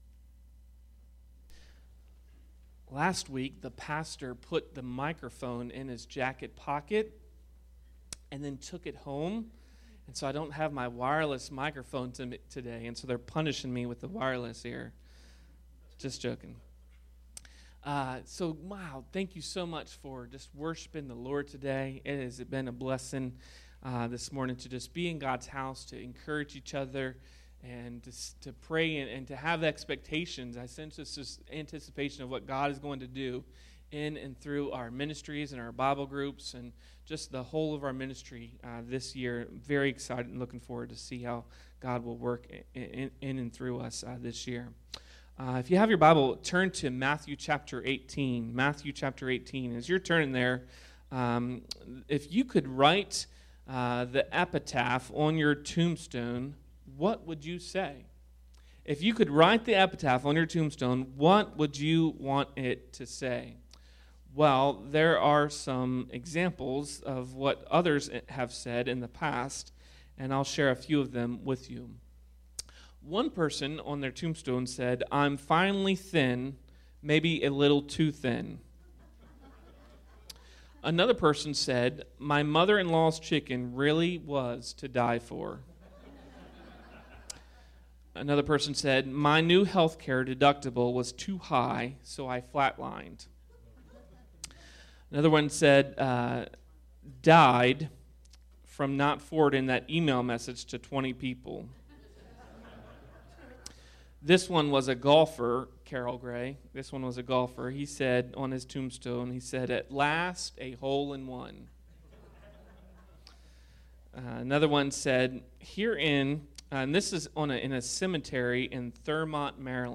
Today’s message will help us understand what it takes to have a great legacy.